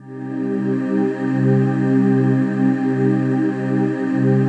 DM PAD2-94.wav